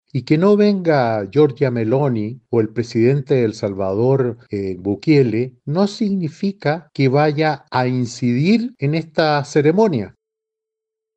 Al respecto, el senador Iván Moreira (UDI) afirmó que la ausencia de estas autoridades no afectará directamente la ceremonia.